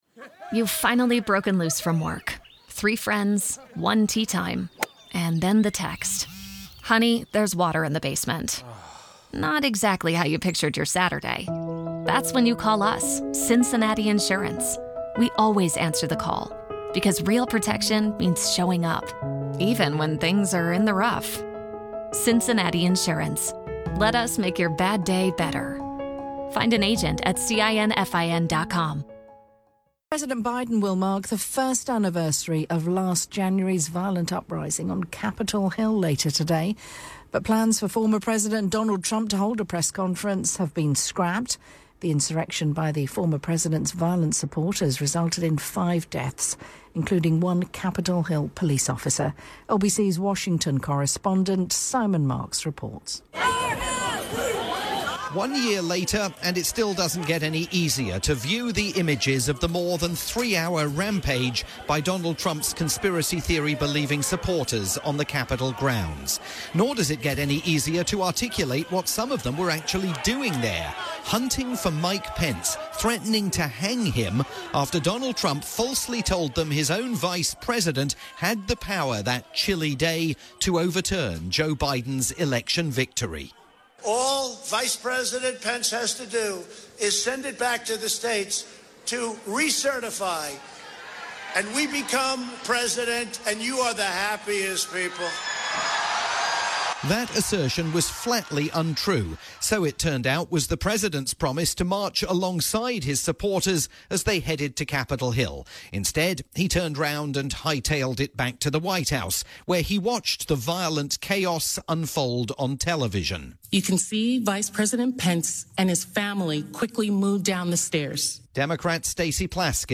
report previewing a big day ahead in the United States, airing on radio stations worldwide including LBC News in the UK.